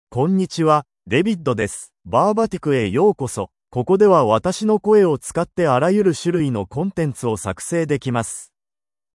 DavidMale Japanese AI voice
David is a male AI voice for Japanese (Japan).
Voice sample
Male
David delivers clear pronunciation with authentic Japan Japanese intonation, making your content sound professionally produced.